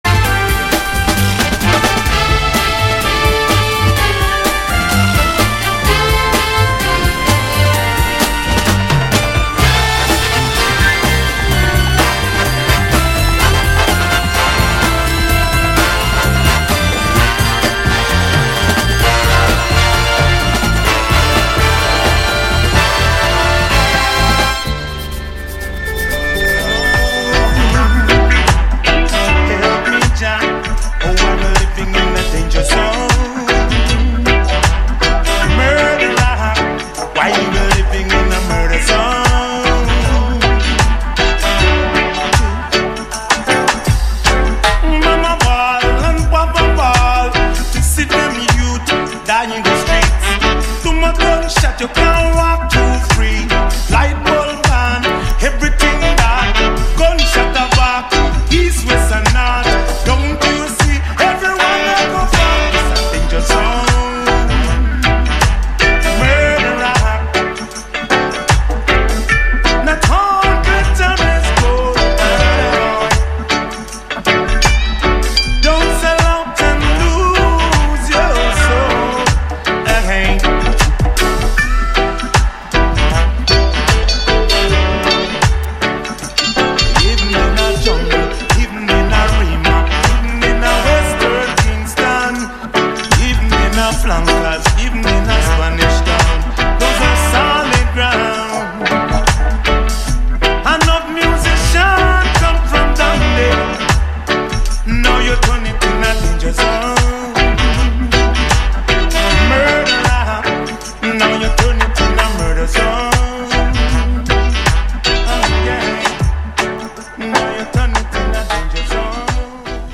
Vous pourrez également retrouver des débats, des interviews et des